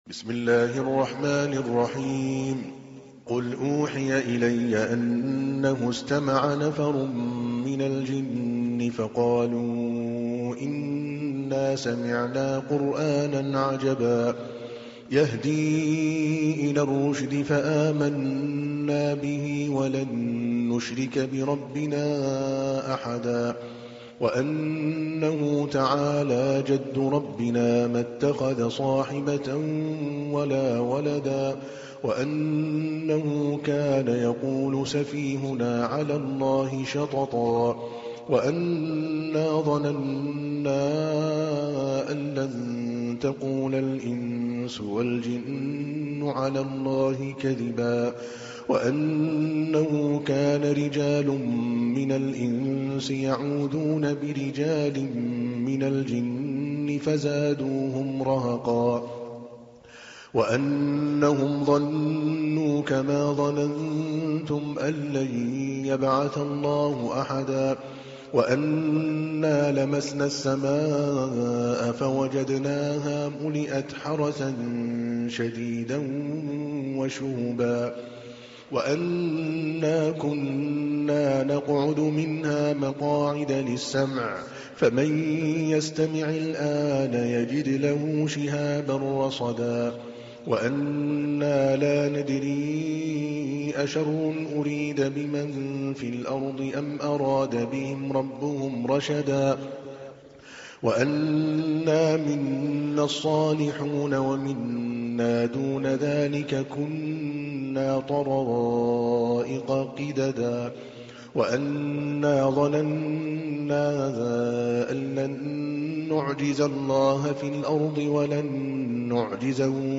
تحميل : 72. سورة الجن / القارئ عادل الكلباني / القرآن الكريم / موقع يا حسين